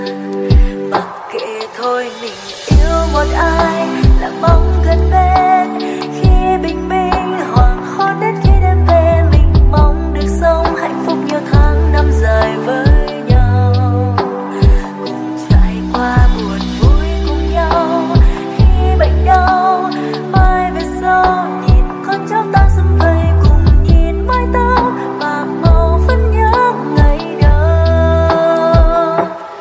Nhạc chuông 7 lượt xem 11/03/2026
- Chìm đắm trong cảm xúc nhẹ nhàng